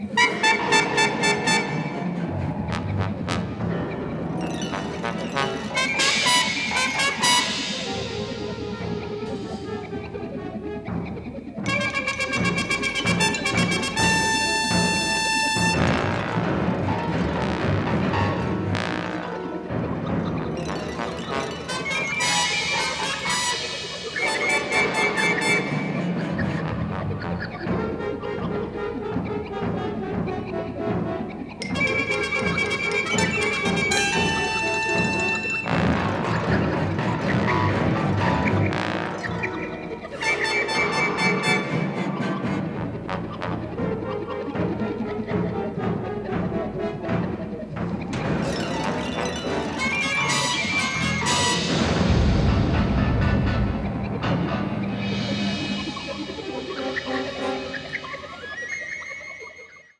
Sound quality is excellent.